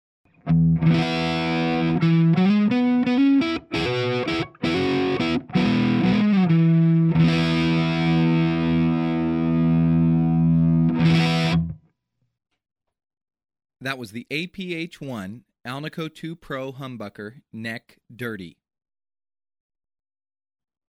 Pickups with an alnico II core are loved for their warm tone, deep basses and sweet trebles, so with the Seymour Duncan APH-1 Alnico II Pro humbucker, you're getting all of that plus legendary, vintage-output with a low string-pull, making it ideal for a range of genres, including classic rock, blues and jazz, while the lush sustain fully supports slide techniques.
APH-1n dirty MP3.
seymour_duncan_aph-1_dirty_neck.mp3